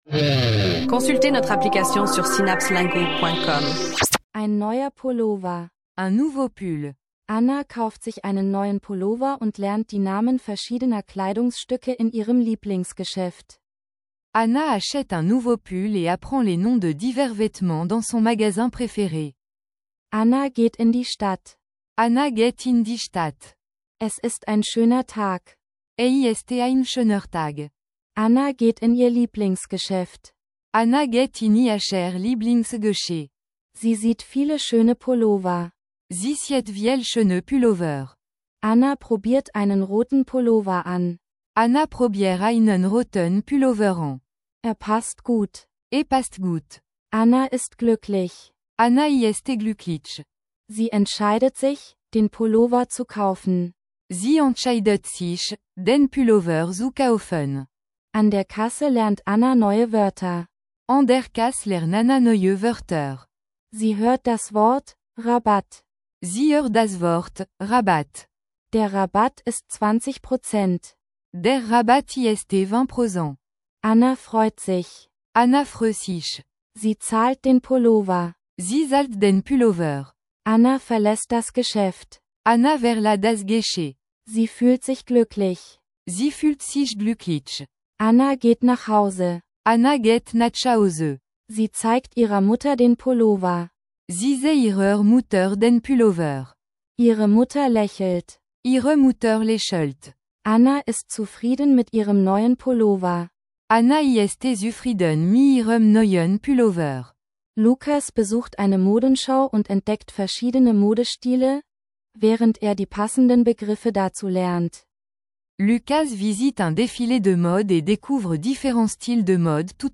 Plongez dans un monde passionnant d’apprentissage des langues avec des textes d’apprentissage divertissants et des chansons entraînantes dans différentes combinaisons de langues.
Écoutez, apprenez et profitez de mélodies accrocheuses qui vous aideront à maîtriser la langue de manière ludique.